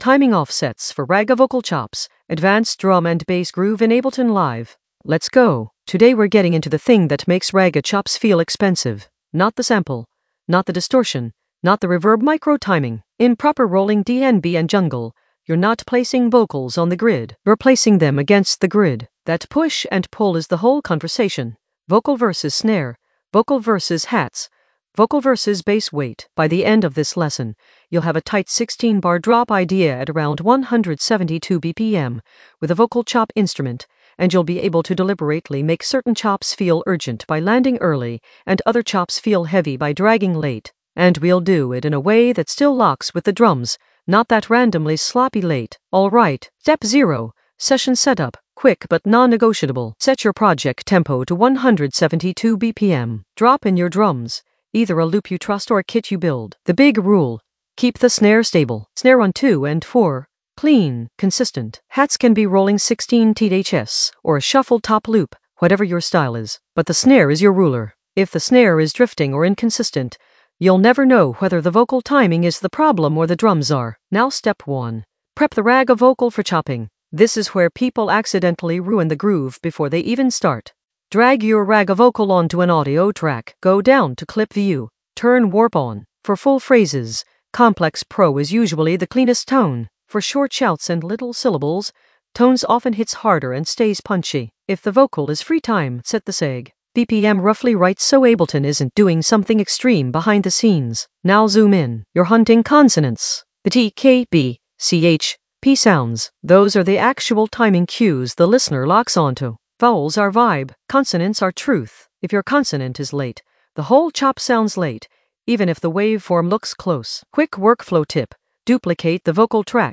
Narrated lesson audio
The voice track includes the tutorial plus extra teacher commentary.
An AI-generated advanced Ableton lesson focused on Timing offsets for ragga vocal chops in the Groove area of drum and bass production.